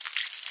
sounds / mob / silverfish / step4.ogg